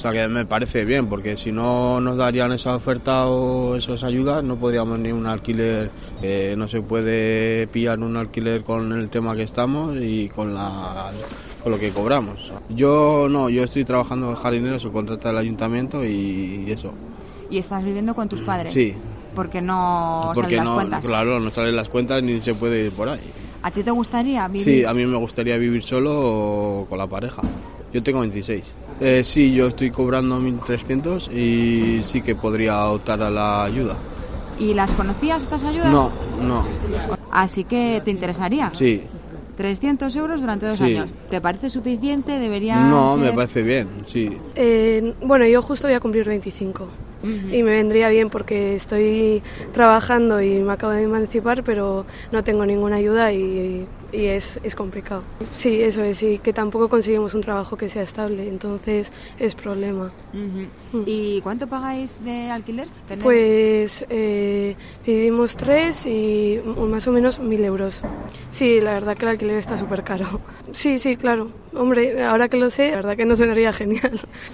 dos jóvenes vascos interesados en las ayudas de emancipacion